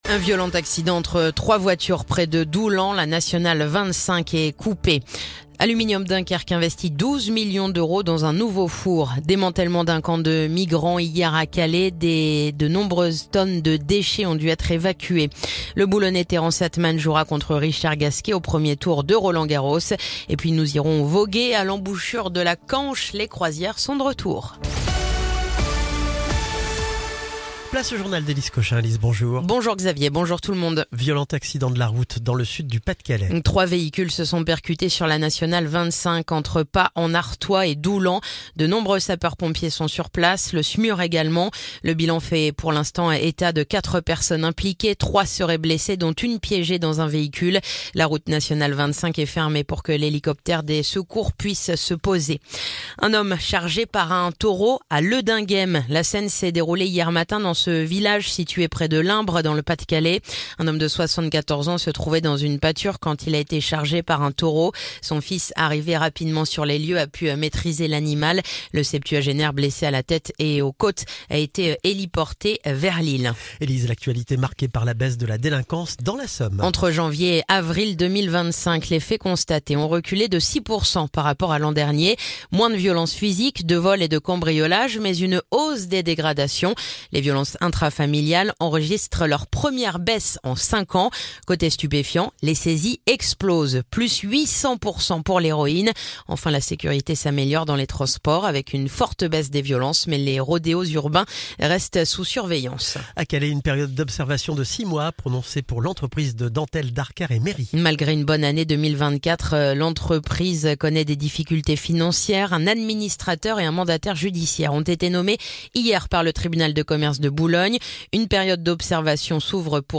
Le journal du vendredi 23 mai